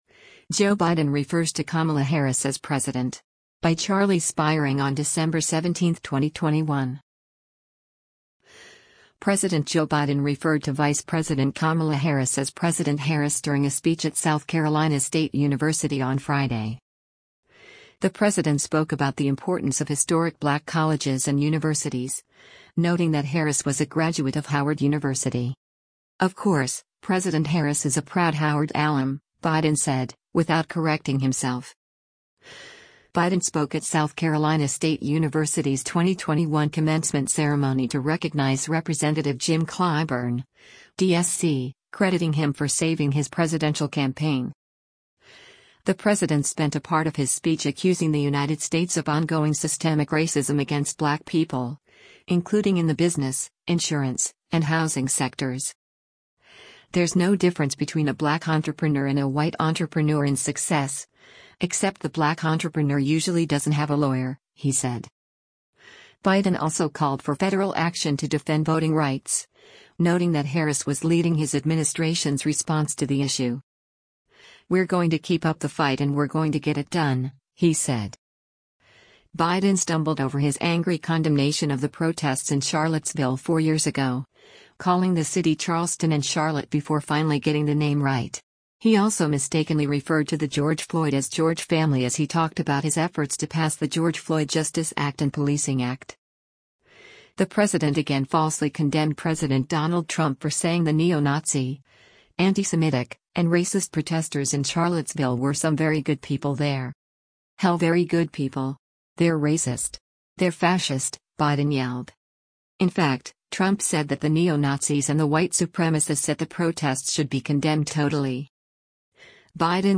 President Joe Biden referred to Vice President Kamala Harris as “President Harris” during a speech at South Carolina State University on Friday.
Biden spoke at South Carolina State University’s 2021 Commencement Ceremony to recognize Rep. Jim Clyburn (D-SC), crediting him for saving his presidential campaign.
“Hell very good people. They’re racist. They’re fascist!” Biden yelled.